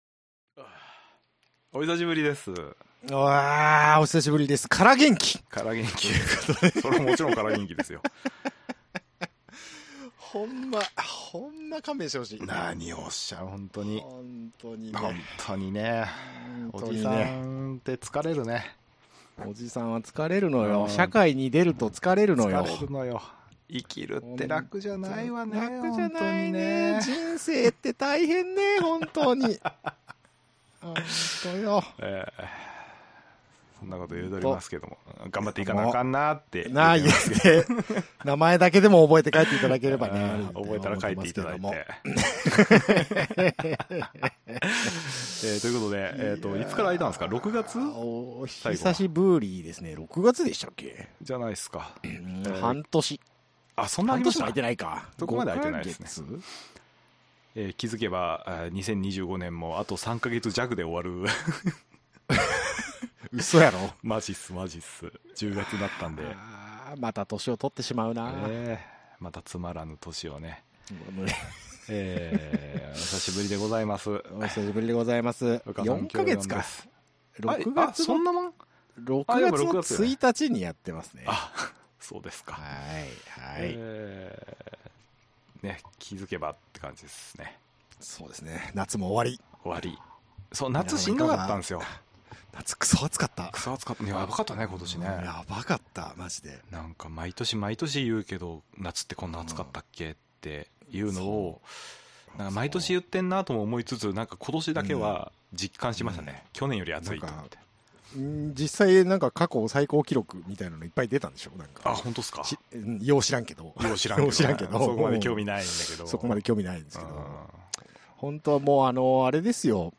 1980年代生まれのおじさん2人がお送りする、嘆きと憂いのボヤキバラエティ番組